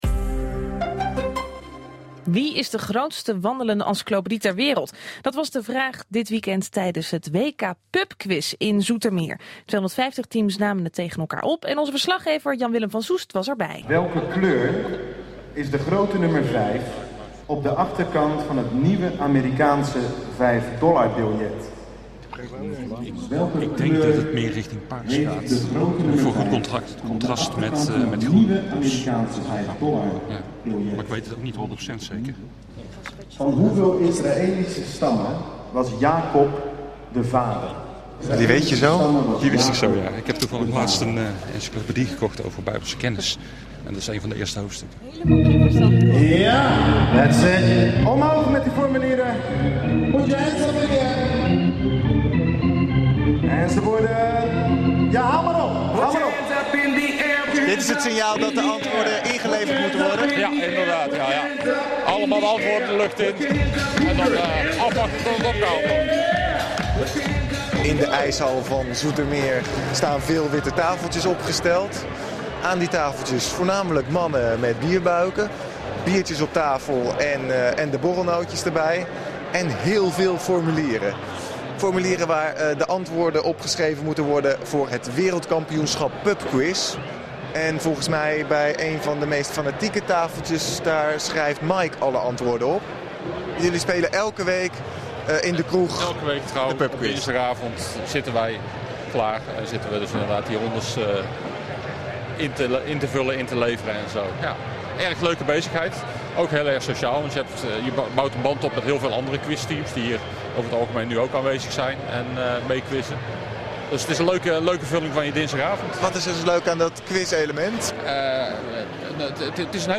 And a audio clip taken from a BNN Radio show with an